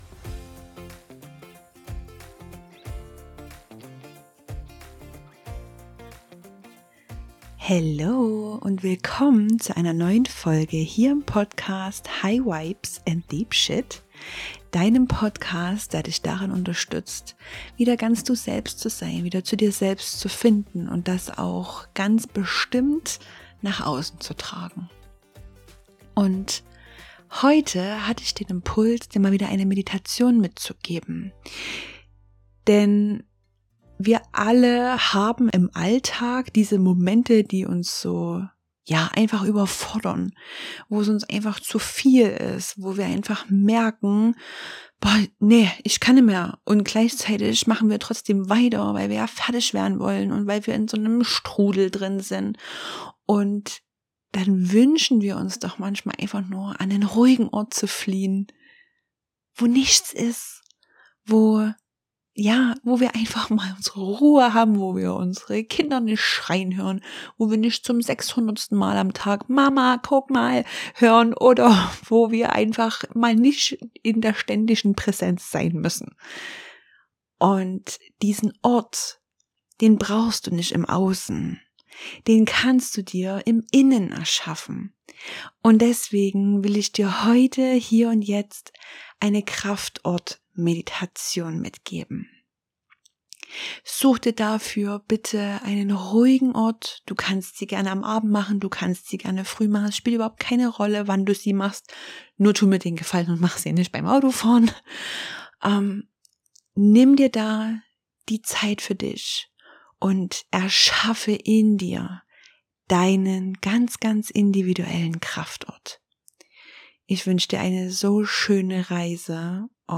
Diese Meditation ist deine Einladung, dem Alltag für einen Moment zu entfliehen.